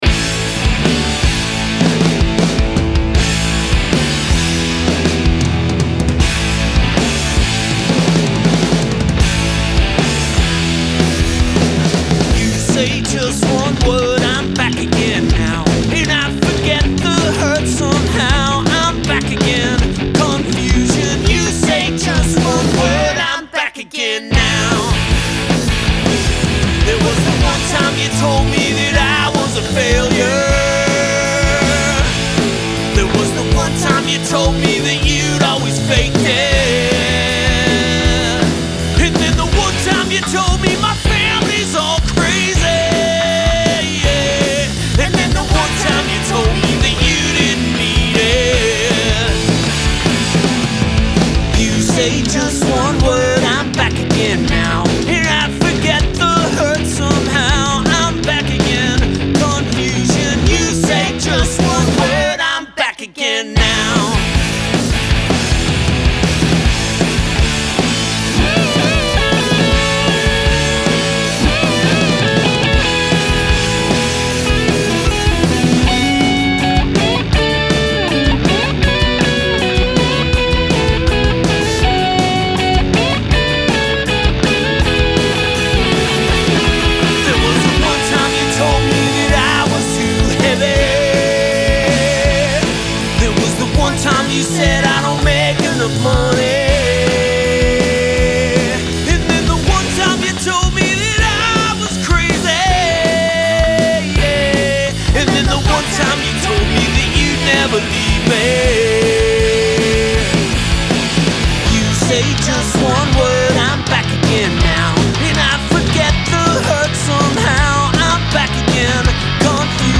It is all at once beautiful, heavy, uplifting, energizing.